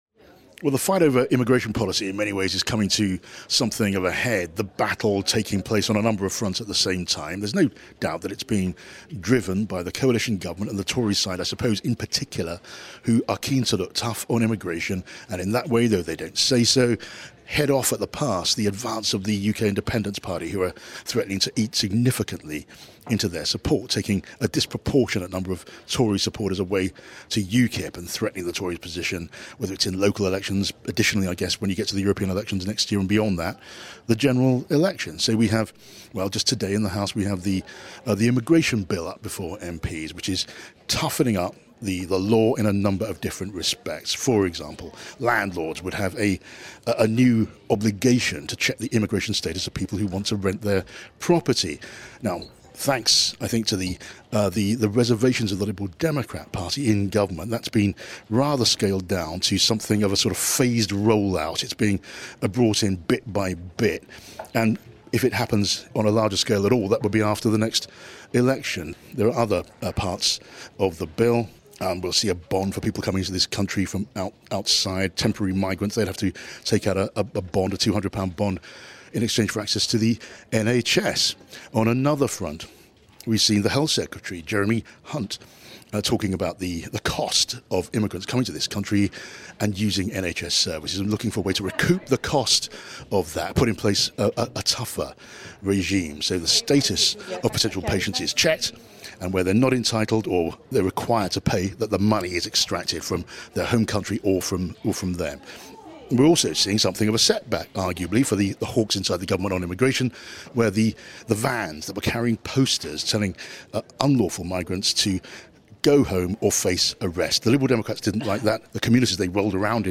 5 live's Chief Political Correspondent, John Pienaar, explains how the immigration debate is playing out at Westminster.